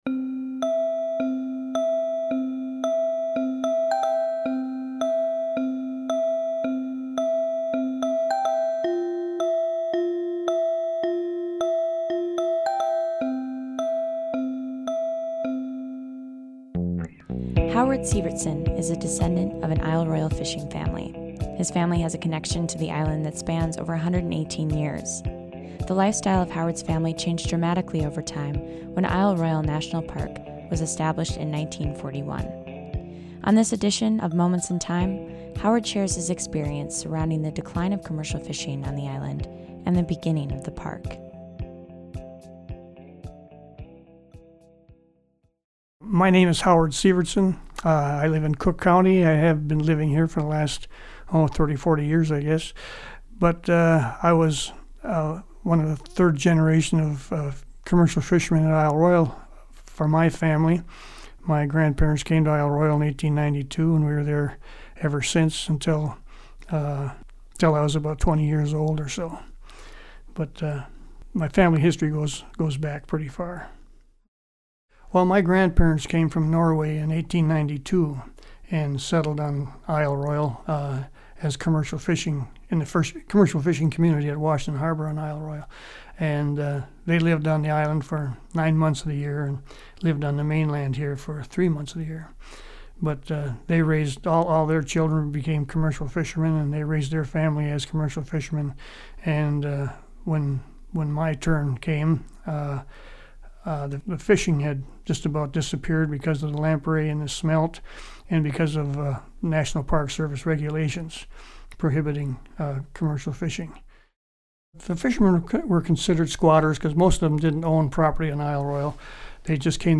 In Moments in Time, we speak with community members about their memories from different periods of our region's past to help foster an appreciation and understanding of the community in which we find ourselves today.